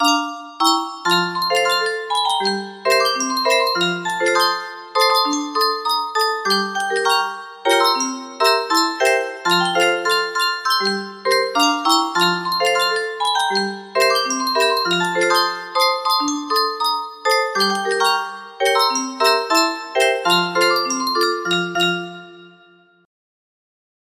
Kolozsvár music box melody
Grand Illusions 30 (F scale)